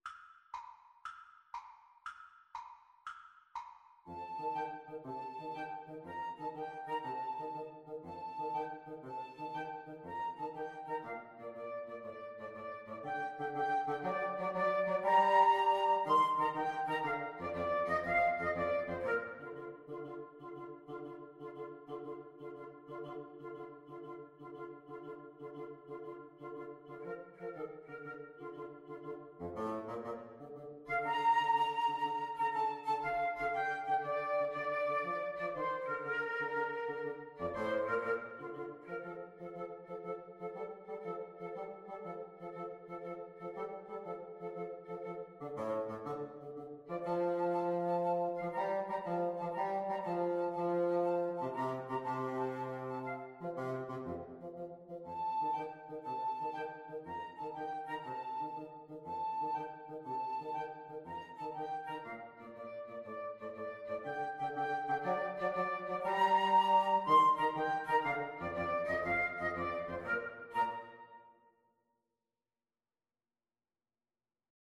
Woodwind Trio version
FluteClarinetBassoon
6/8 (View more 6/8 Music)